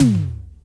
• 1980s Verby Reggae Tome Drum Sample C# Key 36.wav
Royality free tom drum one shot tuned to the C# note. Loudest frequency: 1001Hz